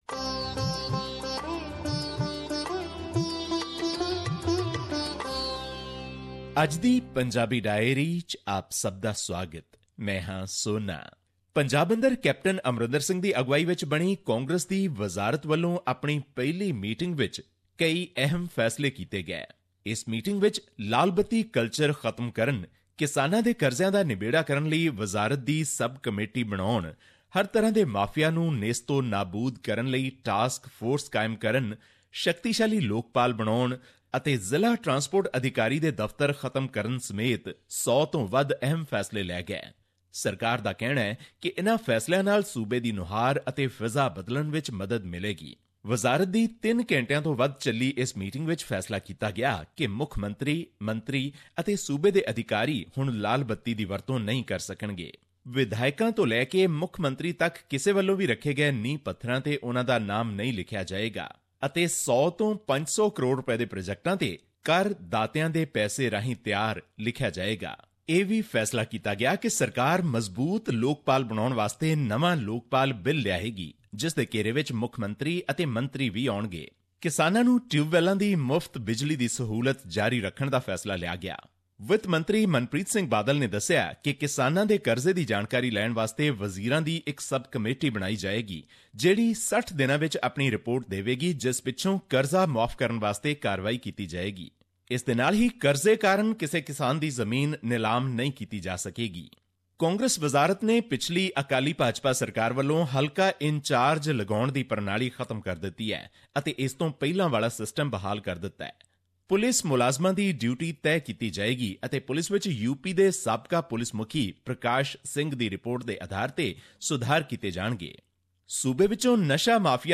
His report was presented on SBS Punjabi program on Monday, Mar 20, 2017, which touched upon issues of Punjabi and national significance in India. Here's the podcast in case you missed hearing it on the radio.